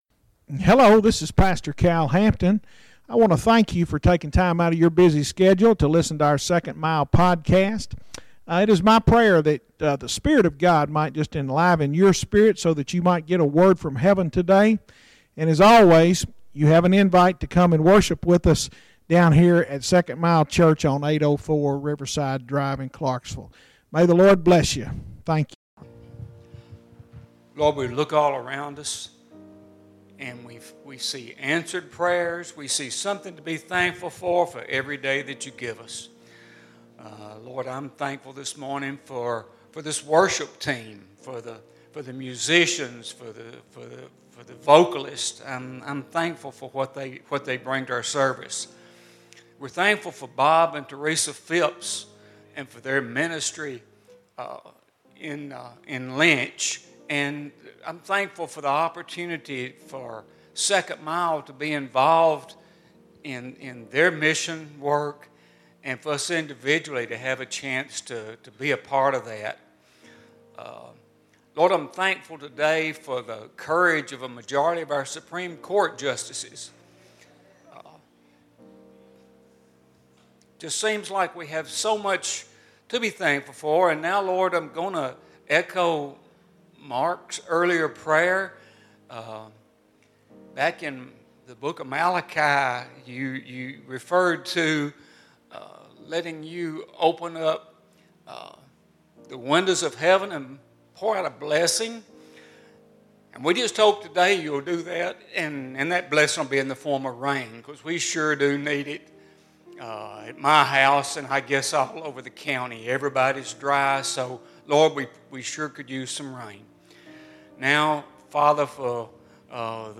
Bible Study Isaiah Ch 1